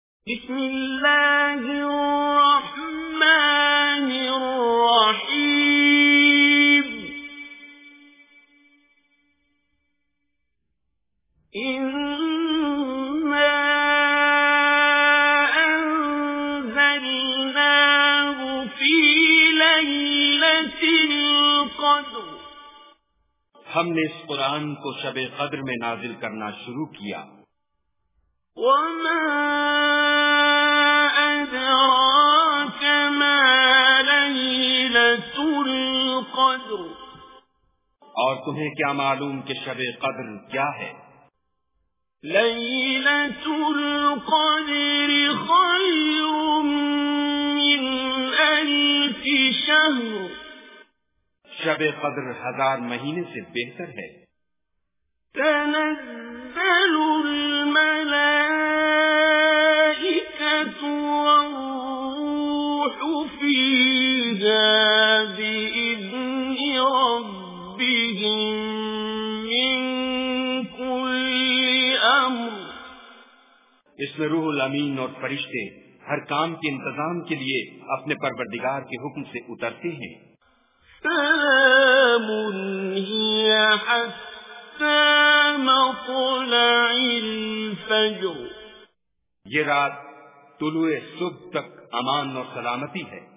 Surah Qadr Recitation with Urdu Translation
Surah Qadr is 97th Surah of Holy Quran. Listen online and download mp3 tilawat / Recitation of Surah Qadr in the beautiful voice of Qari Abdul Basit As Samad.